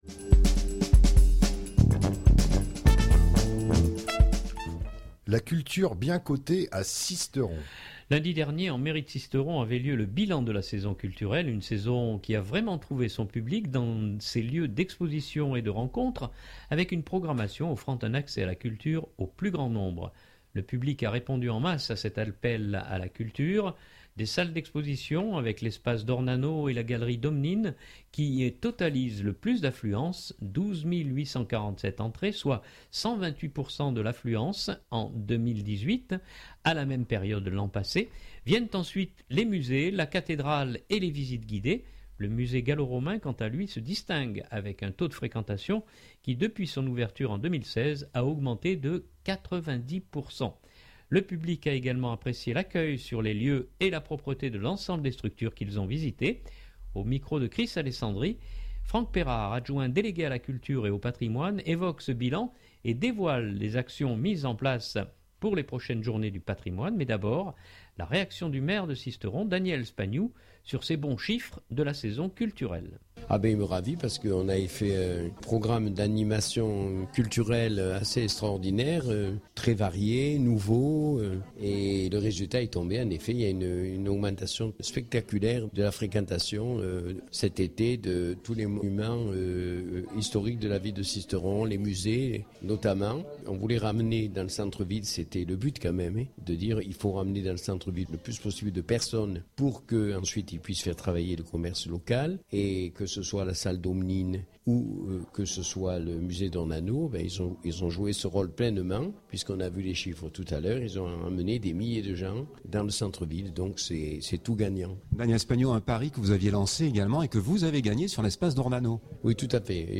Mais d’abord, la réaction du maire de Sisteron Daniel Spagnou, sur ces bons chiffres de la saison culturelle.